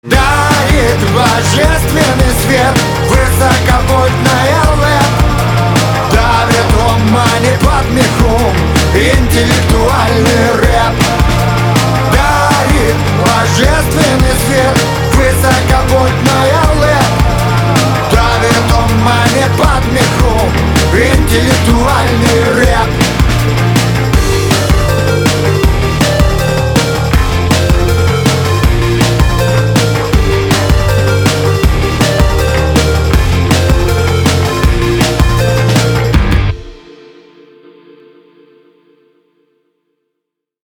ритмичные
Rap-rock
Рэп-рок